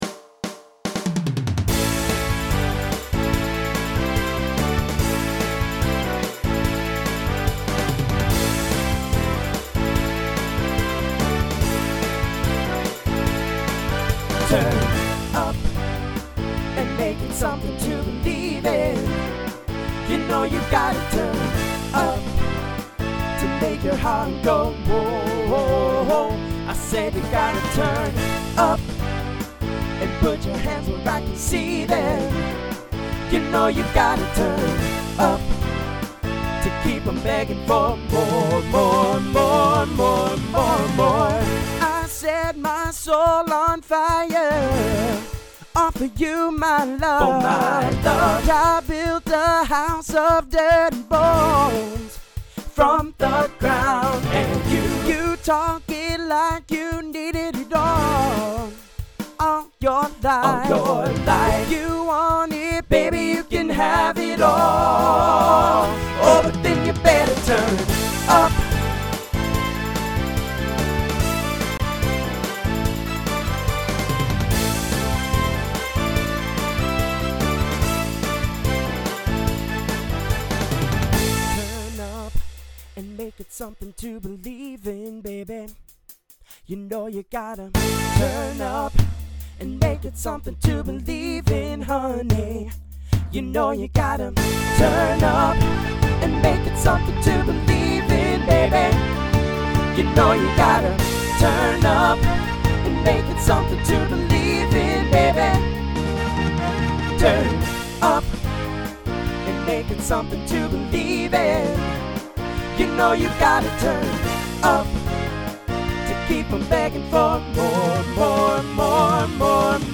Voicing TTB Instrumental combo Genre Pop/Dance